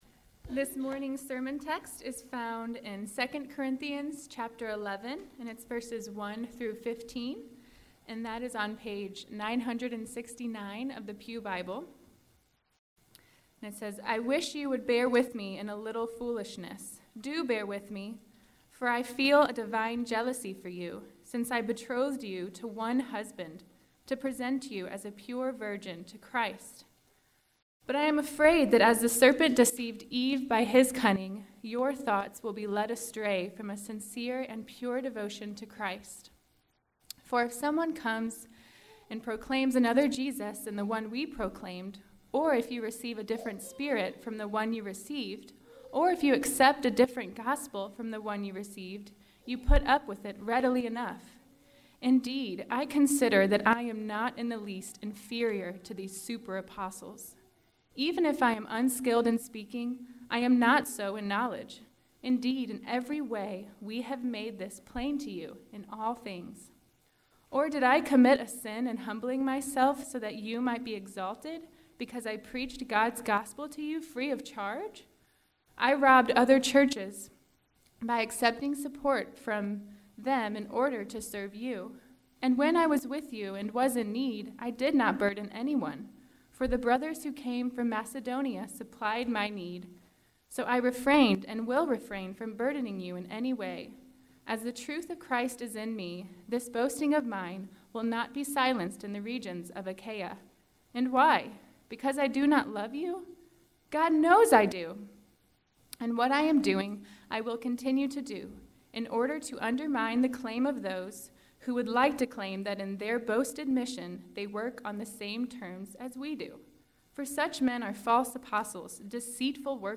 June 4, 2017 Morning Worship | Vine Street Baptist Church